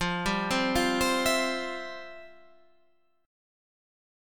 FM7sus2 Chord